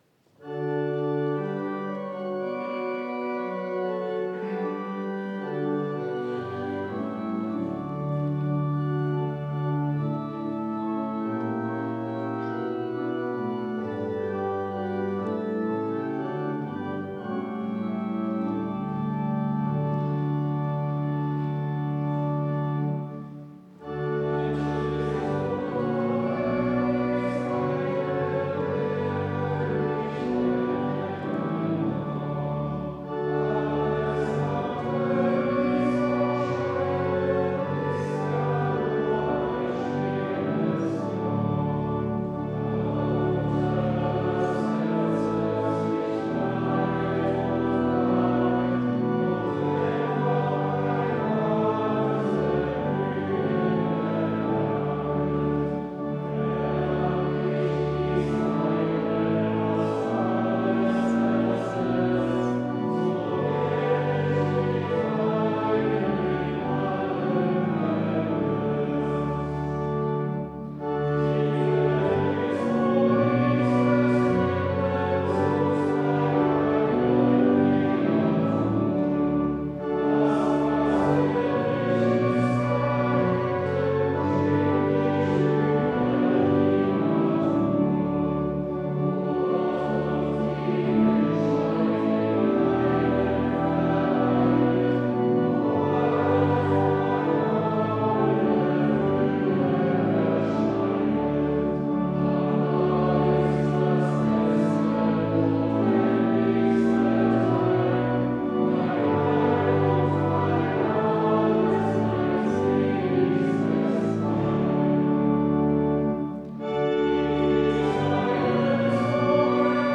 Audiomitschnitt unseres Gottesdienstes vom 2. Sonntag nach Epipanias 2026.